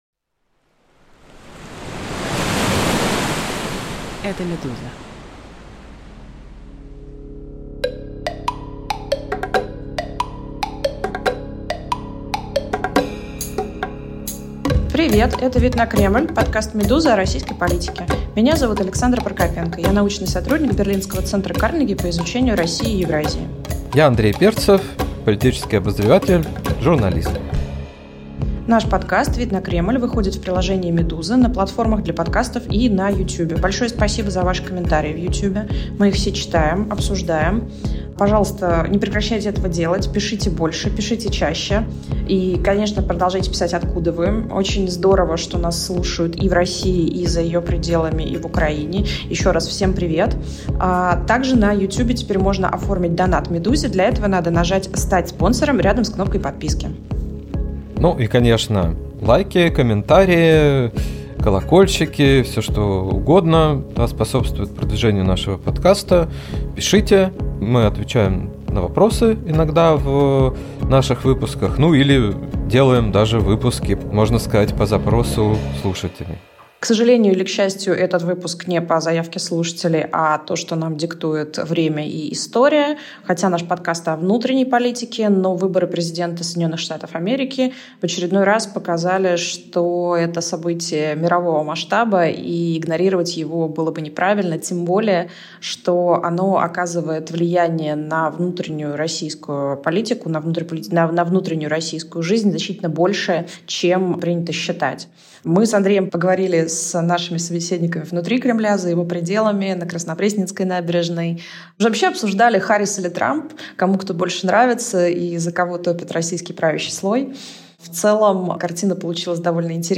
«Вид на Кремль» — подкаст «Медузы» о российской политике. Ведущие — опытные наблюдатели за Кремлем